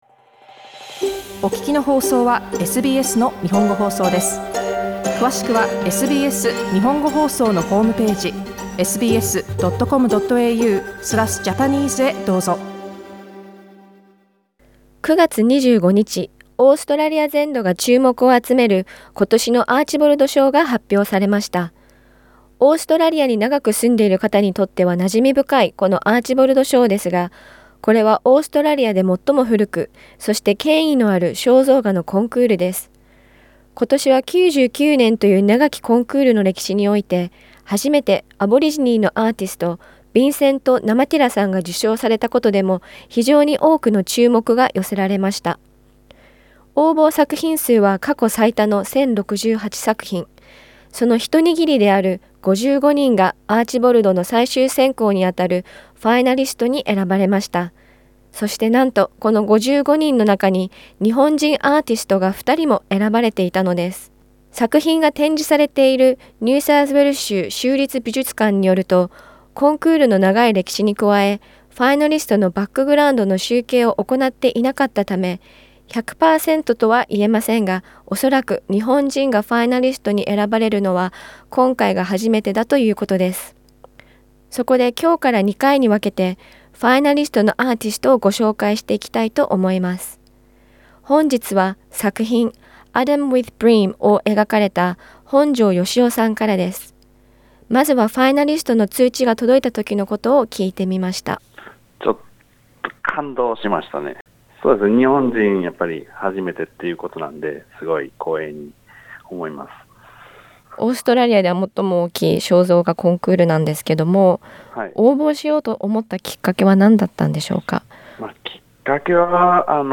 今回は2回に分けて、ファイナリスト入りを果たされた、日本人アーティストのインタビューをお届けします。